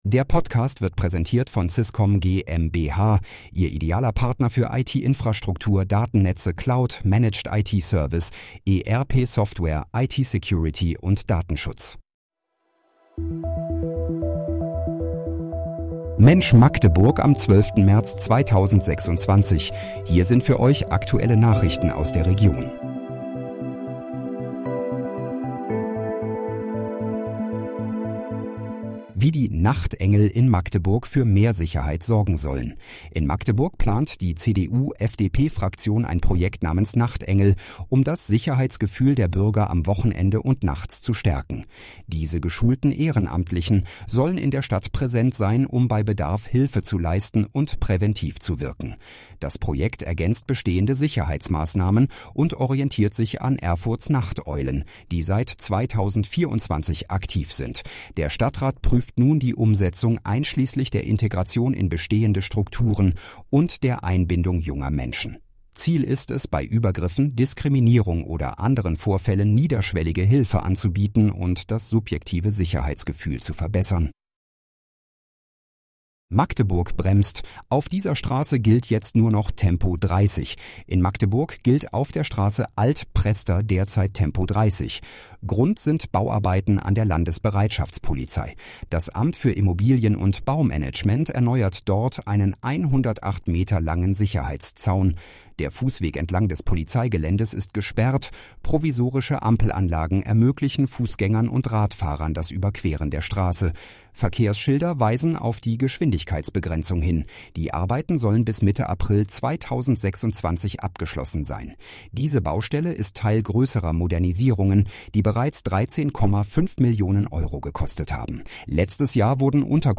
Mensch, Magdeburg: Aktuelle Nachrichten vom 12.03.2026, erstellt mit KI-Unterstützung